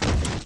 effect__bike_stun.wav